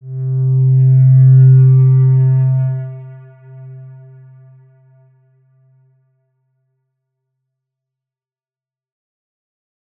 X_Windwistle-C2-pp.wav